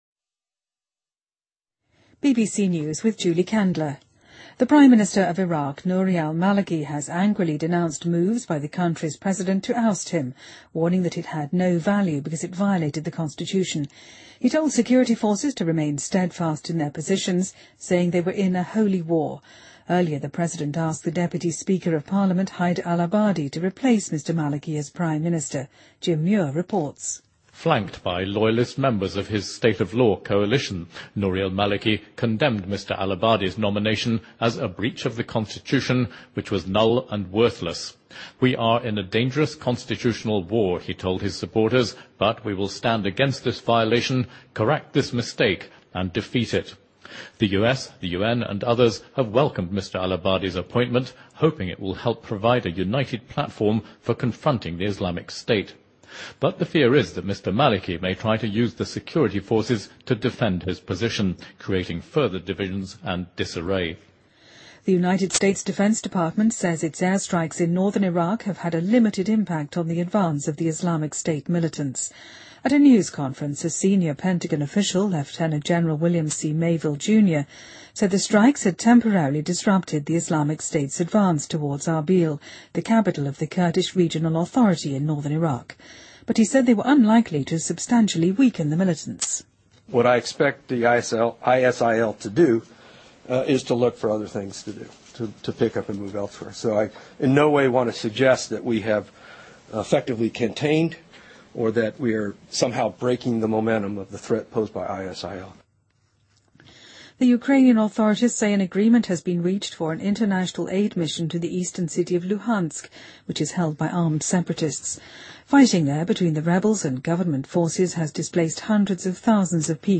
BBC news,伊拉克总理马利基愤怒谴责该国总统要驱逐他的决定